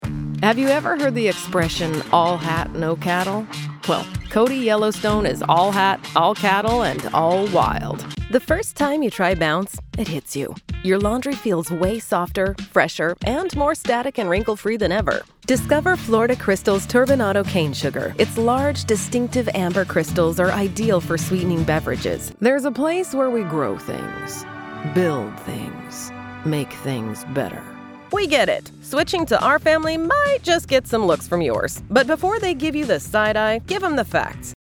Chaleureux
Autoritaire
De la conversation